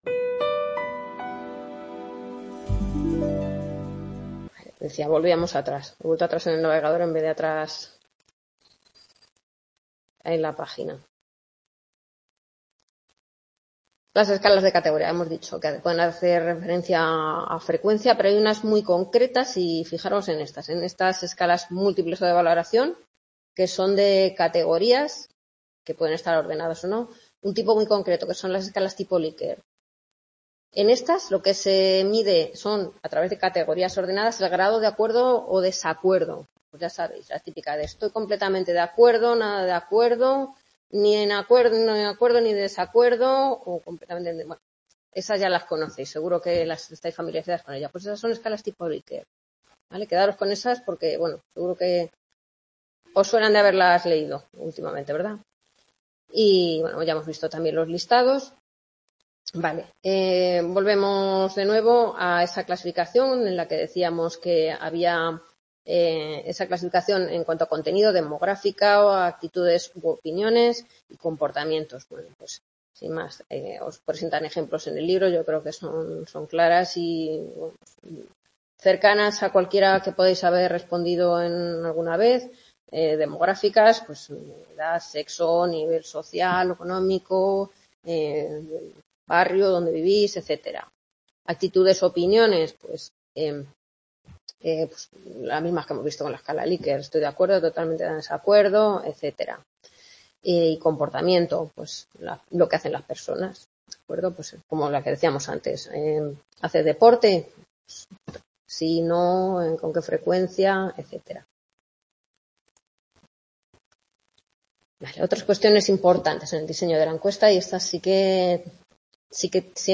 Grabación de la décima tutoría (segunda parte) de la asignatura Fundamentos de investigación del Grado en Psicología impartida en el C.A. Rivas (UNED, Madrid).